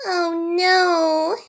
birdo_mamamia.ogg